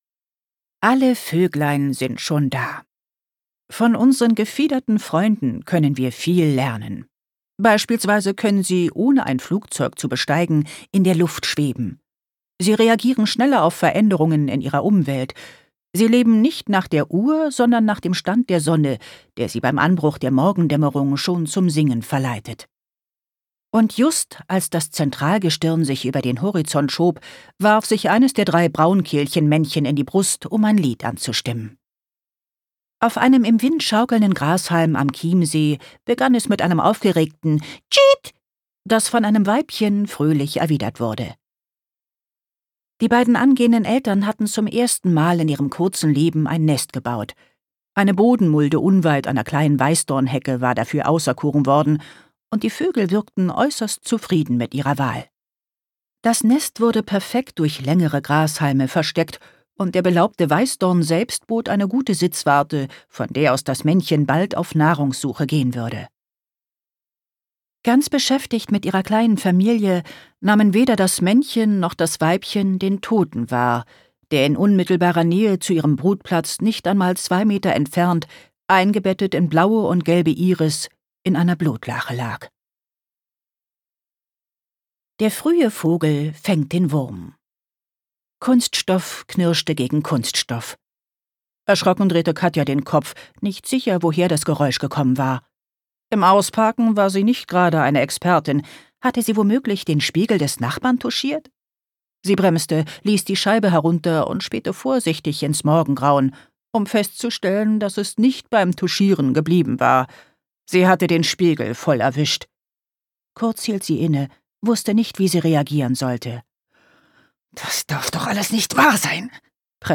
2025 Argon Hörbuch